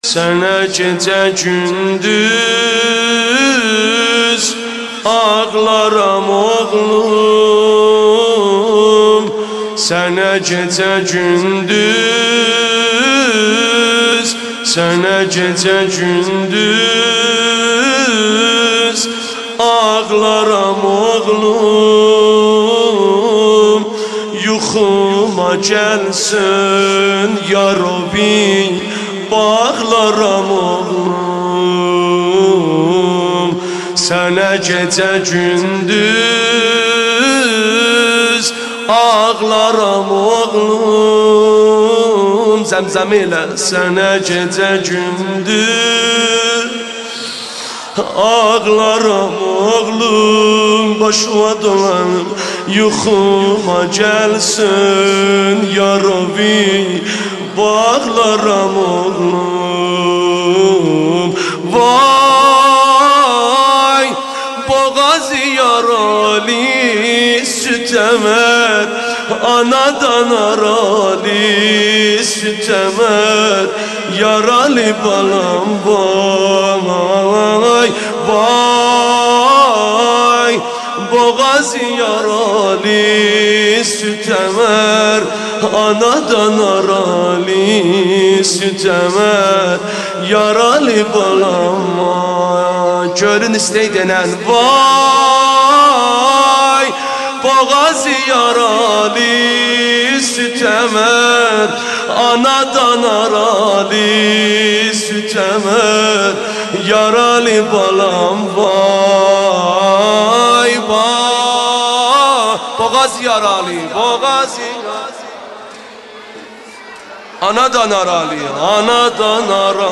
شب هشتم محرم مداحی آذری نوحه ترکی